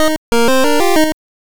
snd_dead.wav